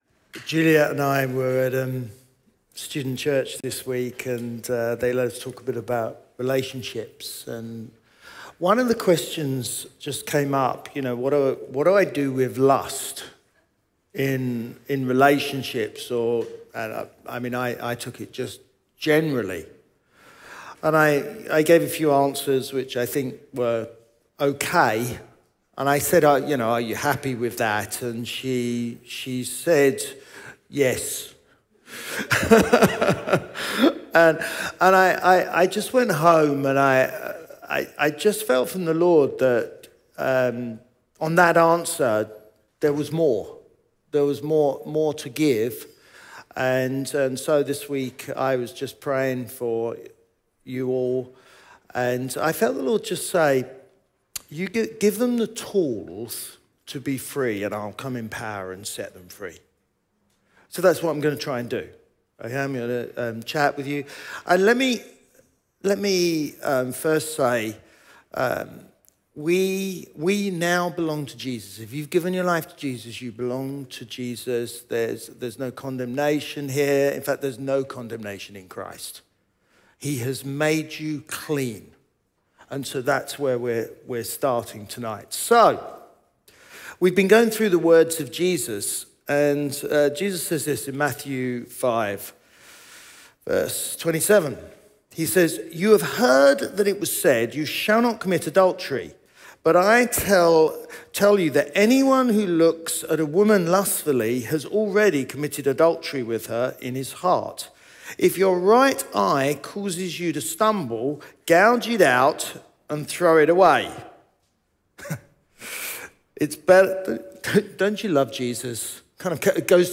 Chroma Church - Sunday Sermon Getting Free from Lust – 5 Practical Tools Feb 10 2023 | 00:39:22 1x 00:00 / 00:39:22 Subscribe Share RSS Feed Share Link Embed